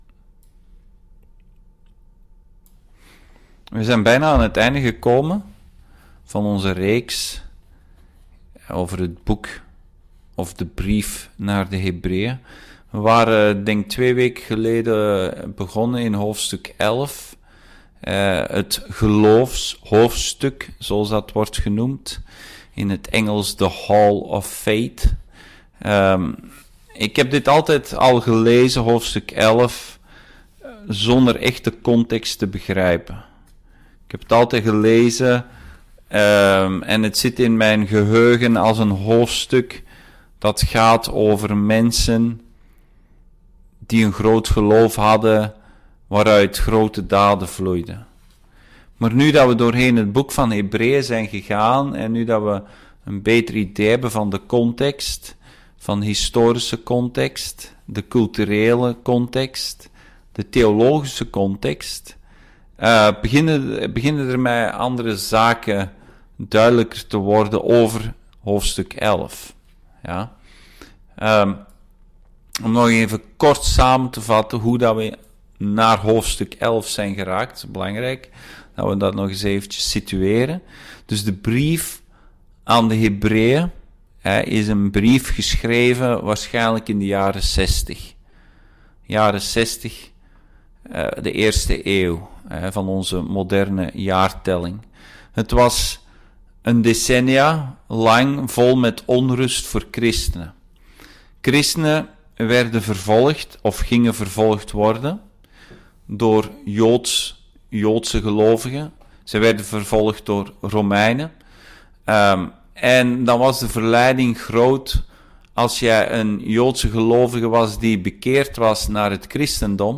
Dienstsoort: Bijbelstudie